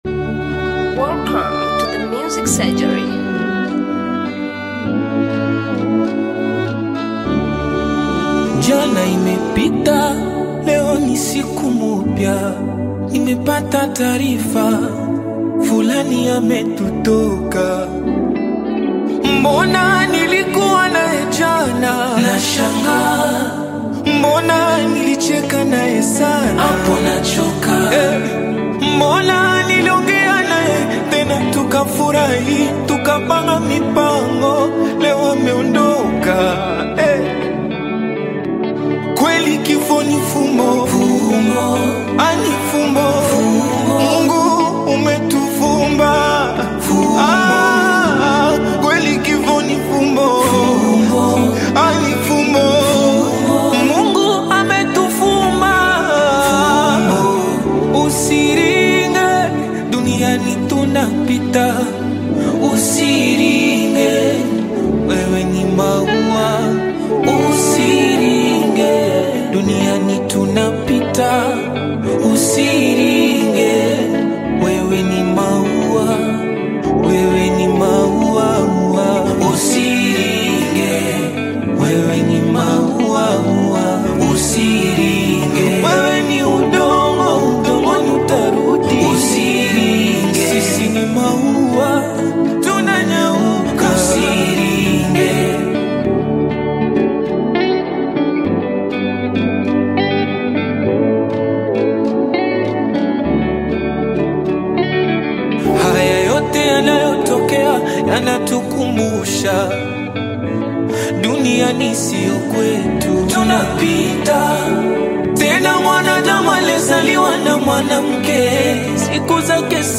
Wimbo wa Maombolezo
Contemporary Gospel
The song is emotional and has significant spiritual weight.